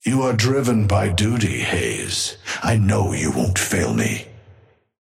Amber Hand voice line - You are driven by duty, Haze. I know you won't fail me.
Patron_male_ally_haze_start_02.mp3